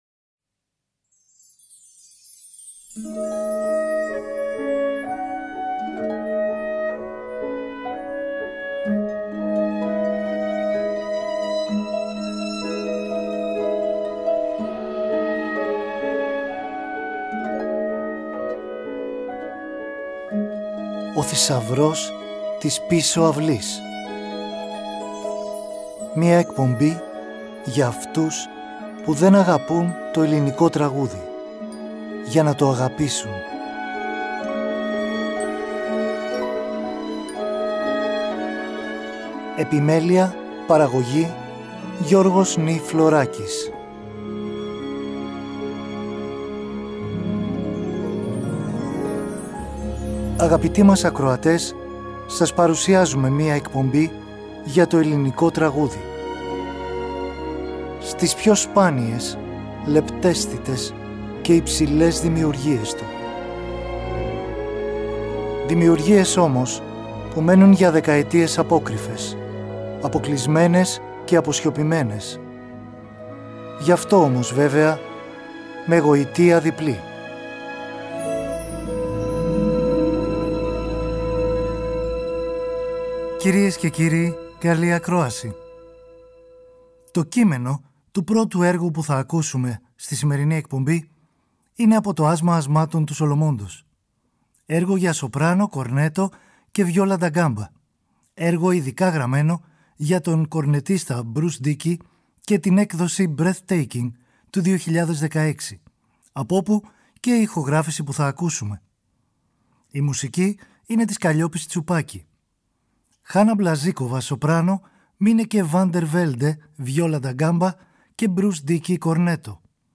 Το Σάββατο 22 Μαρτίου ακούμε έργα των Χάρη Βρόντου σε ποίηση Κ. Π. Καβάφη, Ντίνου Κωνσταντινίδη σε ποίηση Κώστα Βάρναλη, Άλκη Μπαλτά σε παραδοσιακή – δημοτική ποίηση από την Καππαδοκία, Δημήτρη Παπαδημητρίου σε κείμενο από το «Σατυρικόν» του Γάϊου Πετρώνιου και Καλλιόπης Τσουπάκη σε απόσπασμα από το «Άσμα Ασμάτων» του Σολομώντα.
Ελληνικη Μουσικη στο Τριτο